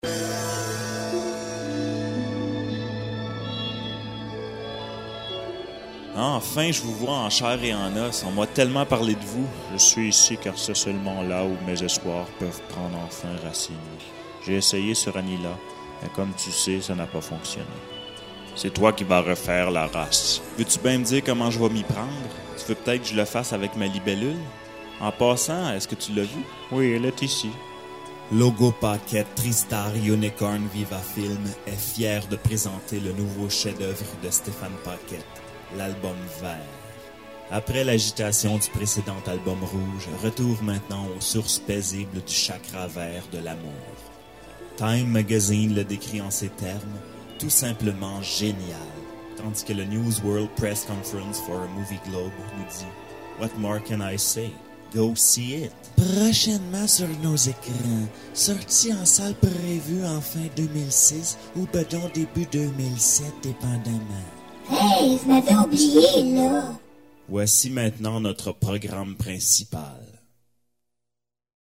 Album organique totalement improvisé contenant les sons d'un seul instrument (à part 1 pièce jouée à l'acoustique), soit ma guitare électrique modifiée pouvant produire 106 combinaisons timbrales dont plusieurs ont des caractéristiques originales très différentes des autres. Certain sons de l'album ont été modifié électroniquement.
Bande annonce de l'album vert présentée sous forme de parodie de bande annonce que l'on retrouve au cinéma.